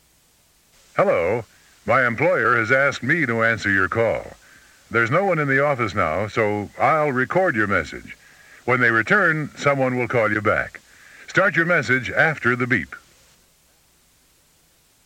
Male Employee
09_MaleEmployee.wav